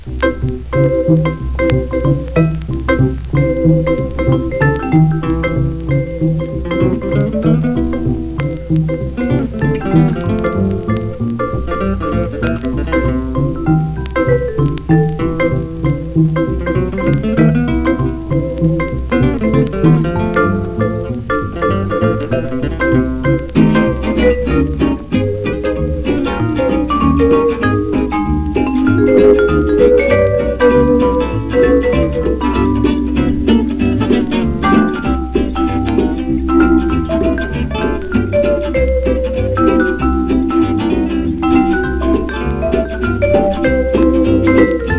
Jazz　Sweden　12inchレコード　33rpm　Mono